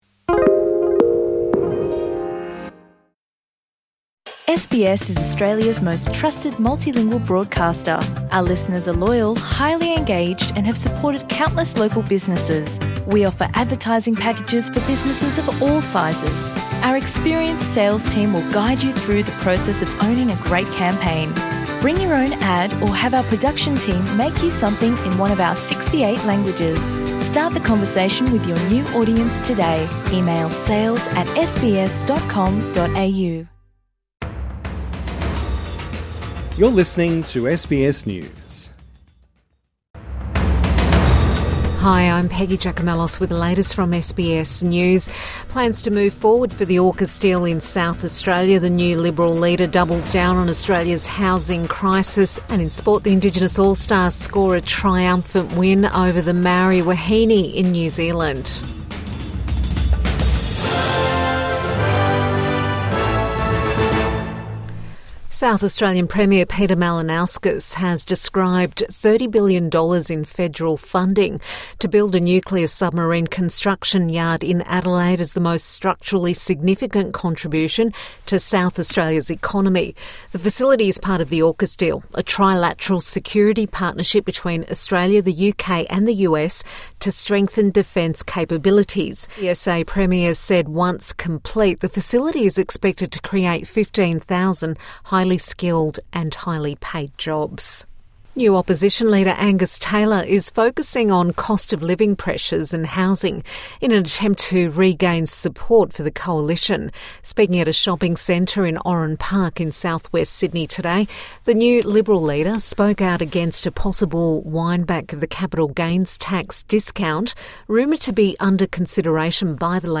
South Australia welcomes funding for AUKUS deal | Evening News Bulletin 15 February 2025